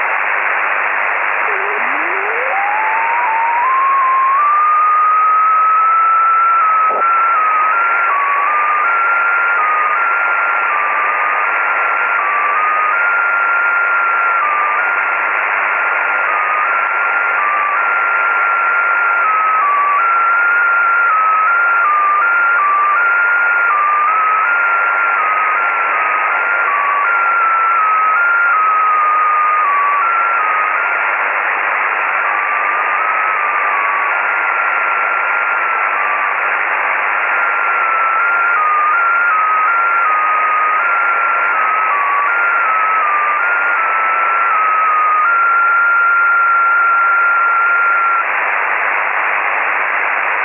• ▲ ▼ Hallo, Ich habe gerade im 15m Band (21.092 MHz) ein mir unbekanntes Signal entdeckt.
Irgendwie sieht es aus wie JT65, nur gespiegelt.